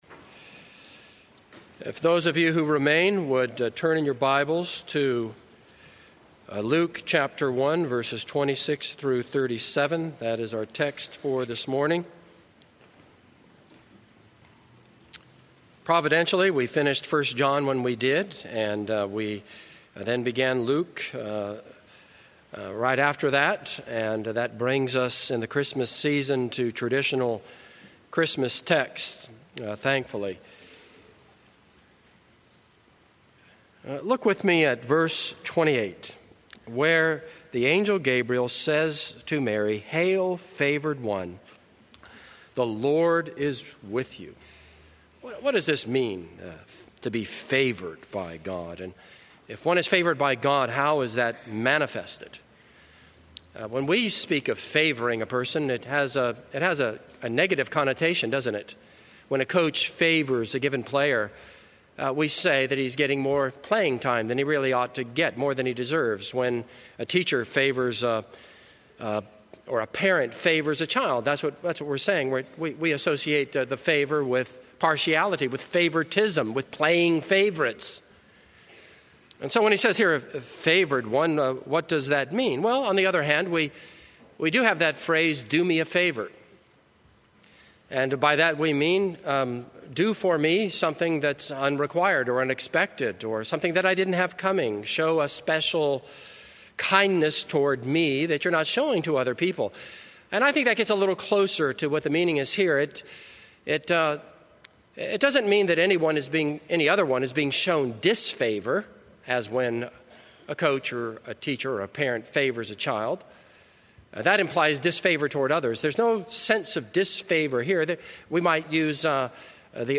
This is a sermon on Luke 1:26-38.